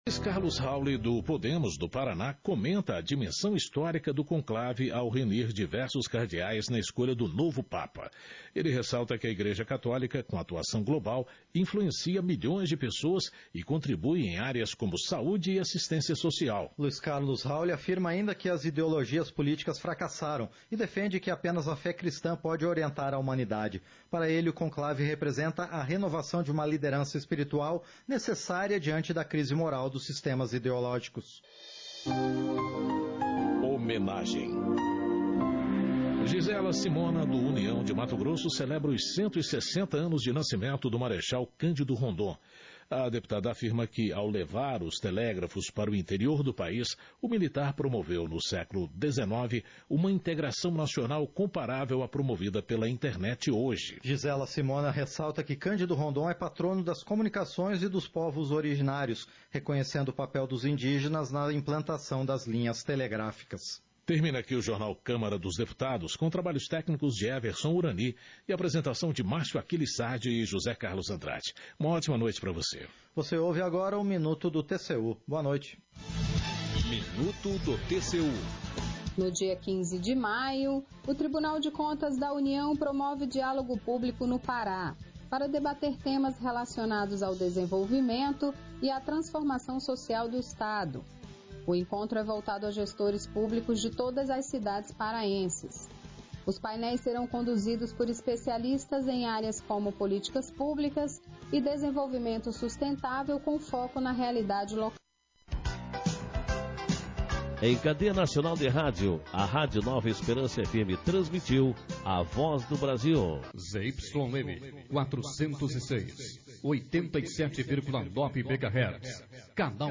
Sessões Plenárias 2025